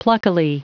Prononciation du mot pluckily en anglais (fichier audio)
Prononciation du mot : pluckily